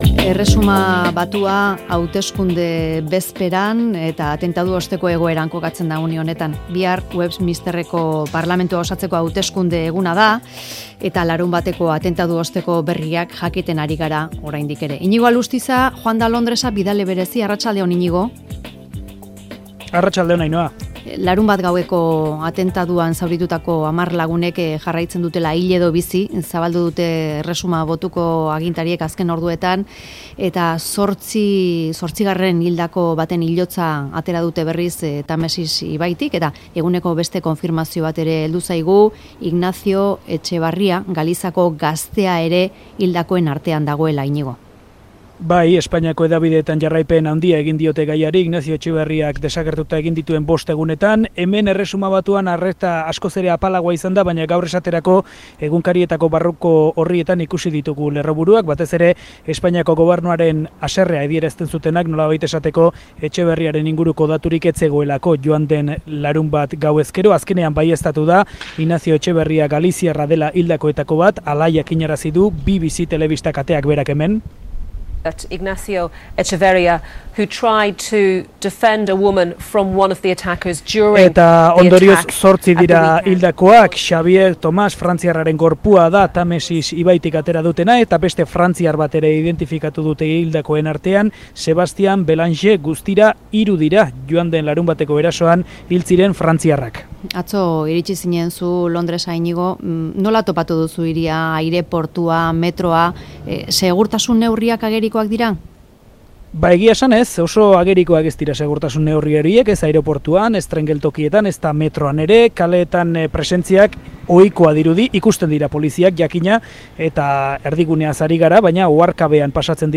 eman dizkigu xehetasunak Londrestik